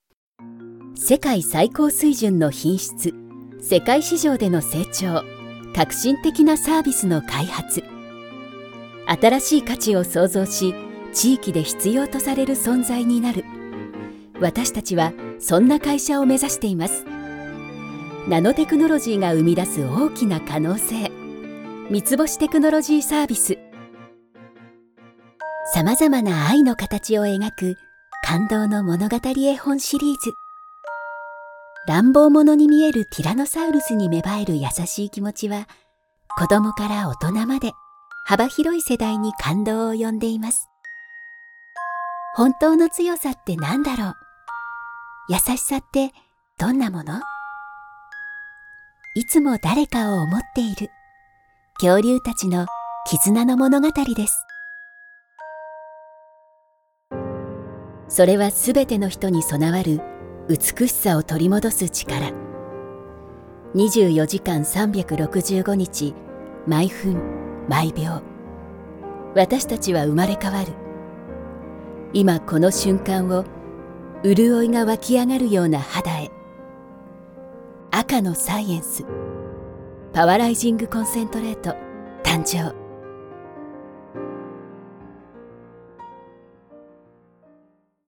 Authentic
Sophisticated
Refreshing